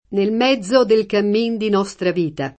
nel m$zzo del kamm&n di n0Stra v&ta] (Dante); cammin facendo — ant. camino [kam&no]: fra l’erba, ond’è verde il camino [fra ll $rba, ond H vv%rde il kam&no] (Petrarca)